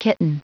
Prononciation du mot kitten en anglais (fichier audio)
Prononciation du mot : kitten